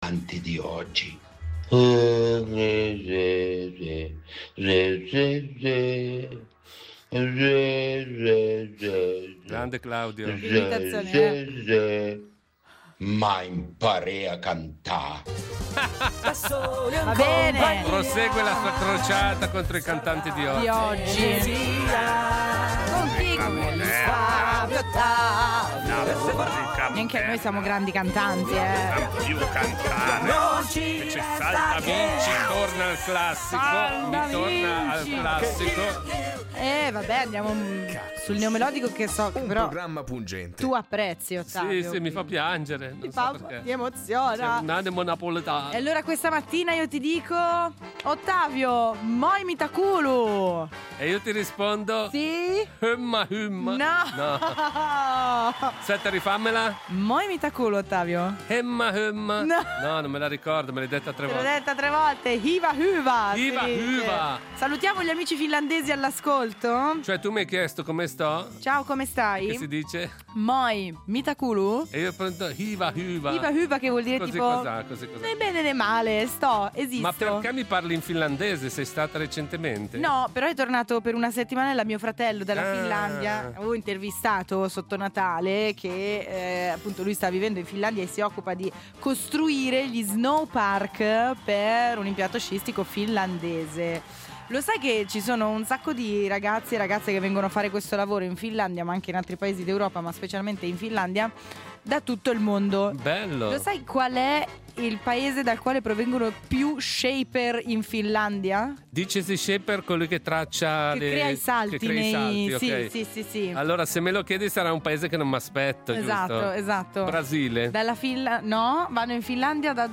Fabrizio Corona arriverà a Lugano per fare una puntata di “Falsissimo”, lo abbiamo intervistato per sapere di cosa parlerà.